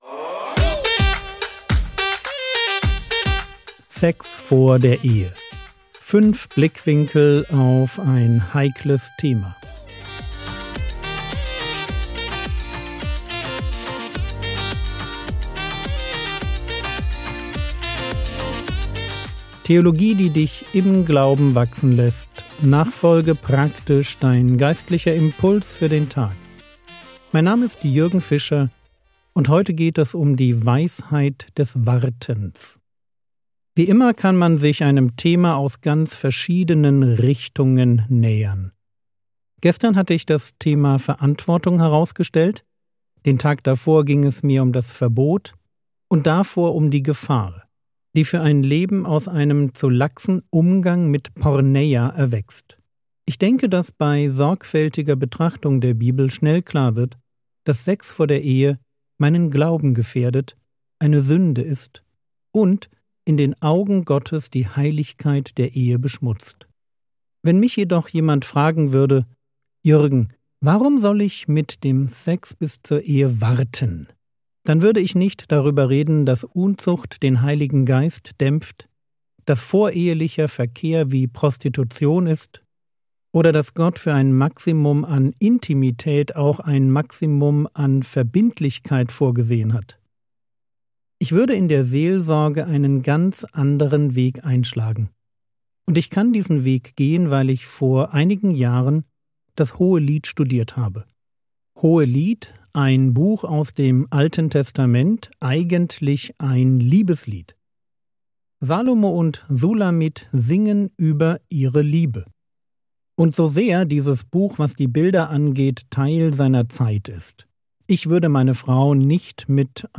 Sex vor der Ehe (4/5) ~ Frogwords Mini-Predigt Podcast